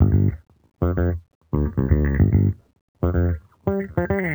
Index of /musicradar/sampled-funk-soul-samples/110bpm/Bass
SSF_JBassProc2_110G.wav